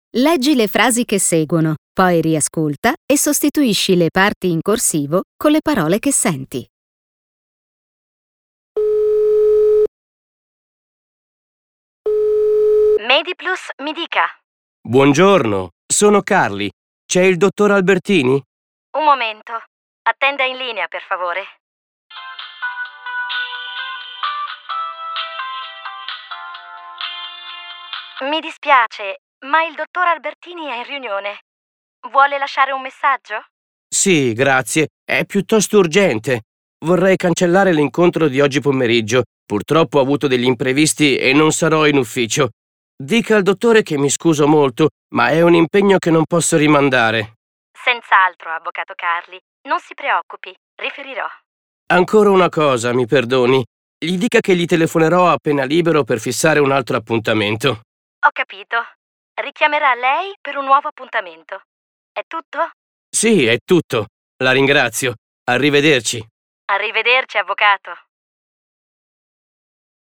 Telefonate (brani audio)